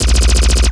LASRGun_Alien Handgun Burst_03_SFRMS_SCIWPNS.wav